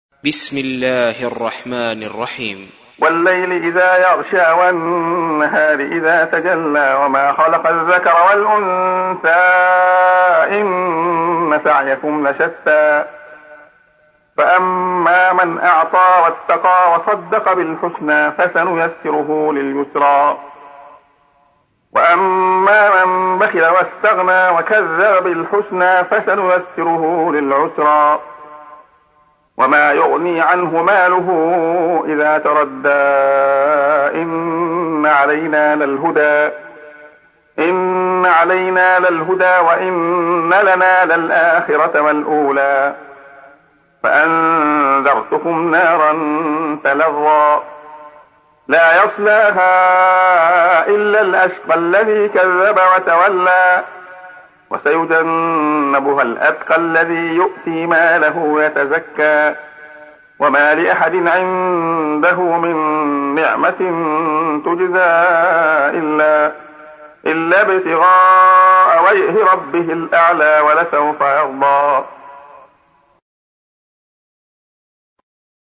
تحميل : 92. سورة الليل / القارئ عبد الله خياط / القرآن الكريم / موقع يا حسين